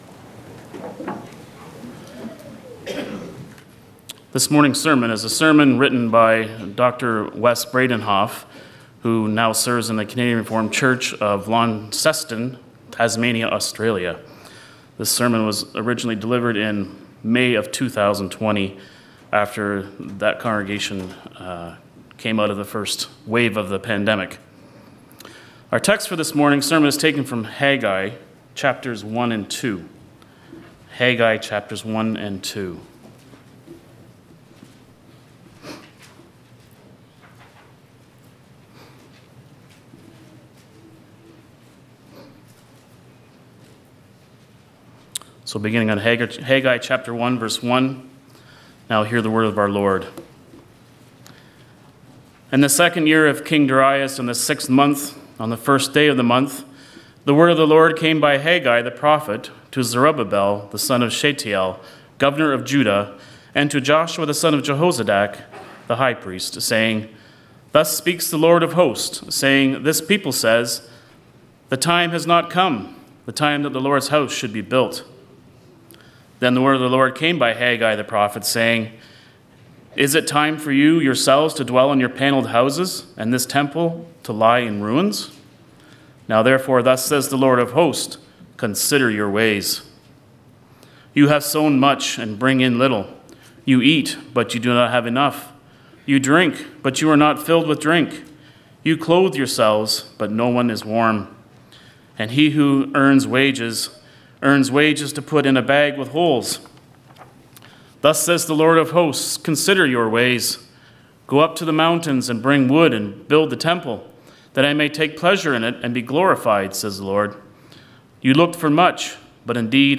5.Sermon.mp3